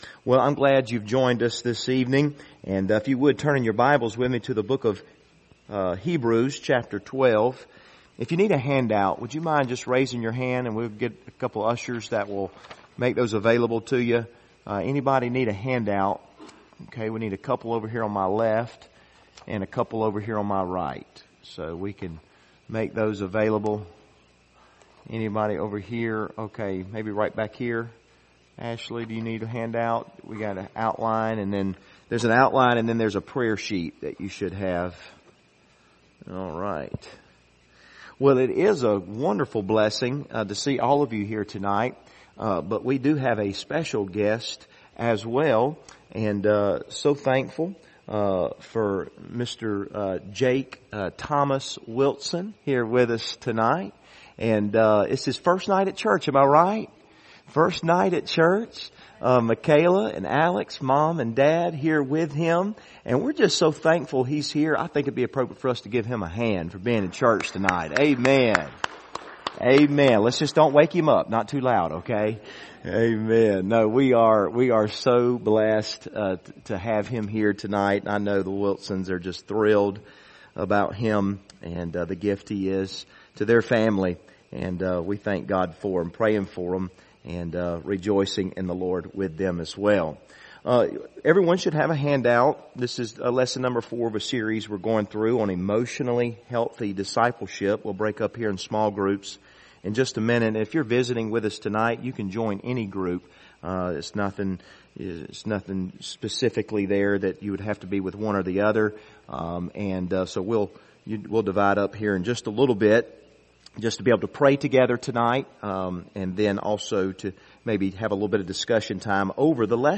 Passage: Hebrews 12 Service Type: Wednesday Evening